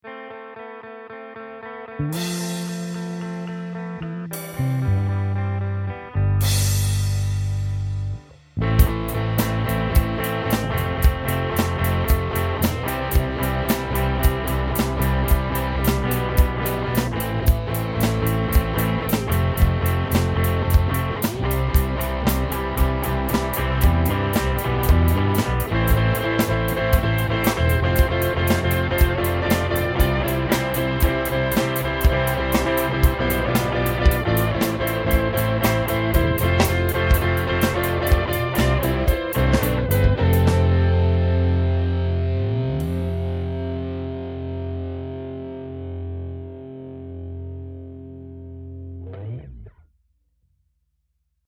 4/4 rock beat